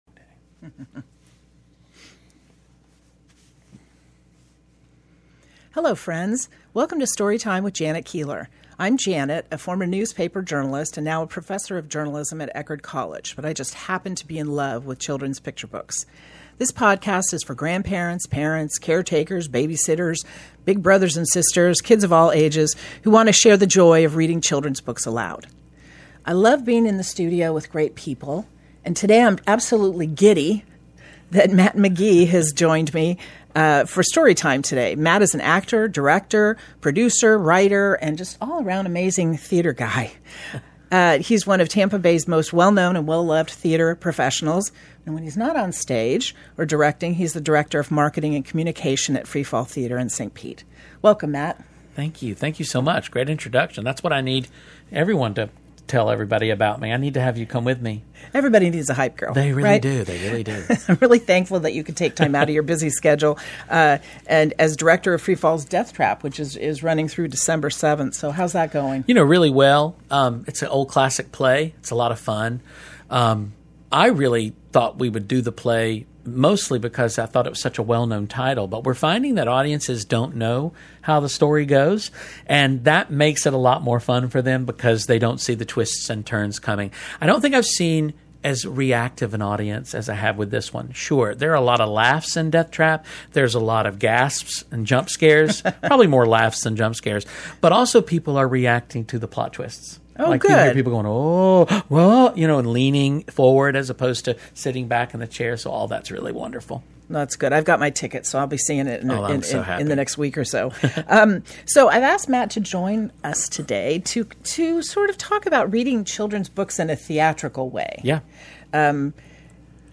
STORY TIME
Her STORY TIME podcast is for grandparents, parents, babysitters, caretakers and kids of all ages who want to share the joy of reading children’s books aloud.